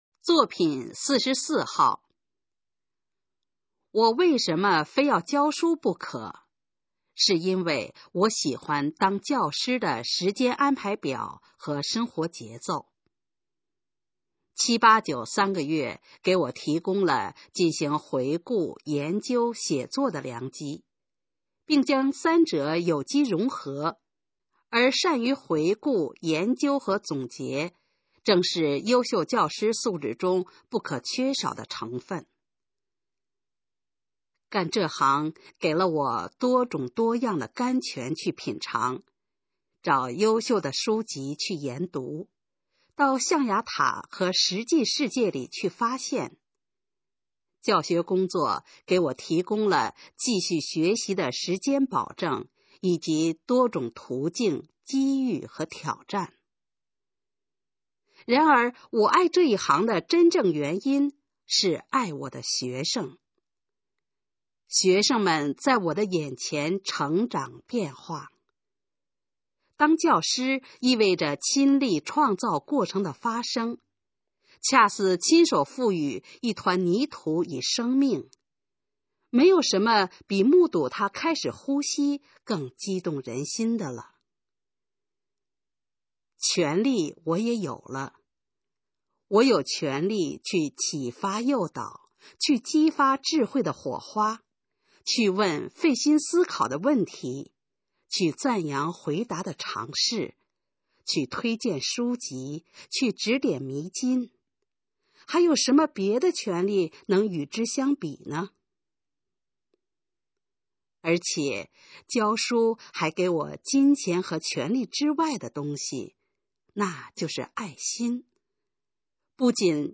《我为什么当教师》示范朗读_水平测试（等级考试）用60篇朗读作品范读